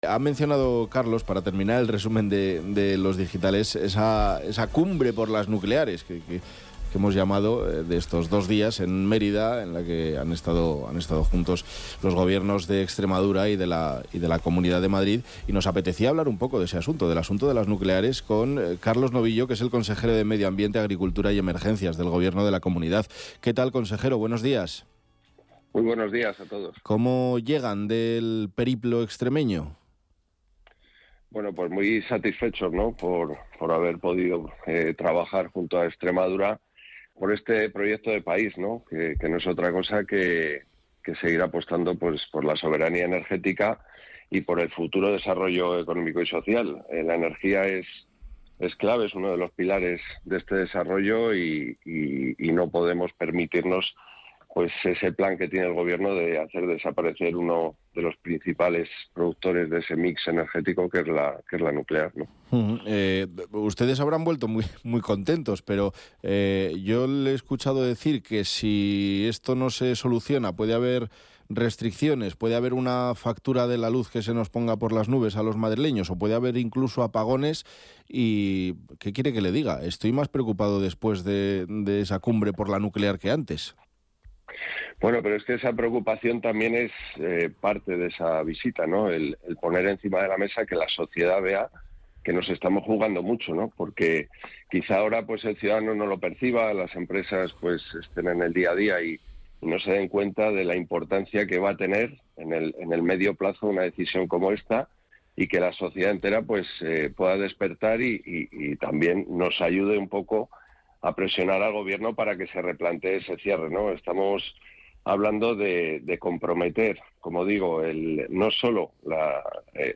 Carlos Novillo habla en Onda Madrid sobre el cierre de la central nuclear de Almaraz Carlos Novillo habla en Onda Madrid sobre el cierre de la central nuclear de Almaraz
Carlos Novillo, consejero de Medio Ambiente, Agricultura y Emergencias de Madrid, ha destacado en una entrevista en Buenos Días Madrid de Onda Madrid que esta alianza busca “apostar por la soberanía energética y el futuro desarrollo económico y social”.